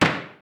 whack.ogg